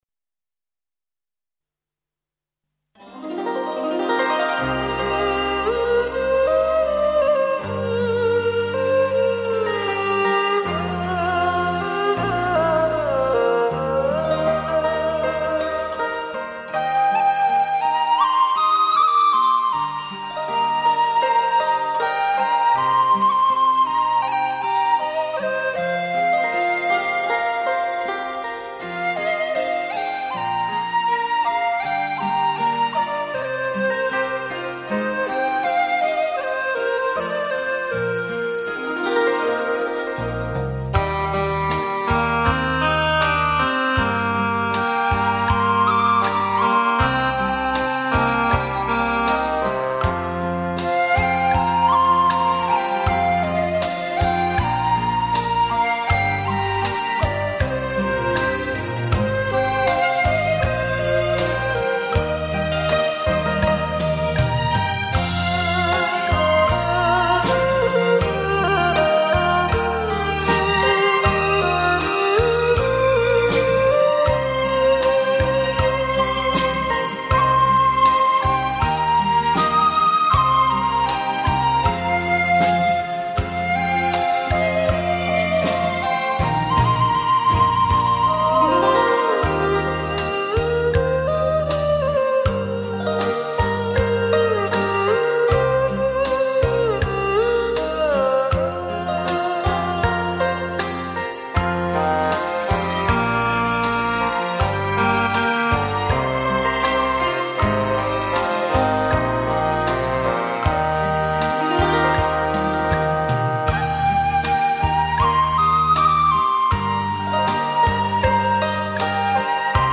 民族乐器与电子乐的结合，演绎一首首经典老歌，顾名思义“怀旧篇”。